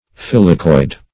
Search Result for " filicoid" : The Collaborative International Dictionary of English v.0.48: Filicoid \Fil"i*coid\, a. [L. filix, -icis, fern + -oid: cf. F. filicoi["i]de.]